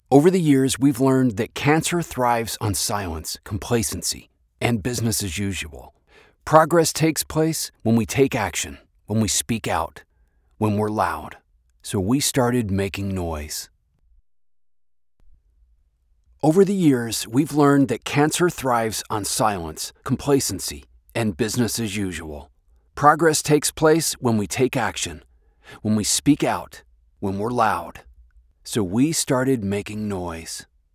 okay here is the A/B with no processing
U87ai vs. ???? (no processing)
The first one is the U87ai, the second one is the IA78u.
Mic_A_vs._Mic_B_unprocessed.mp3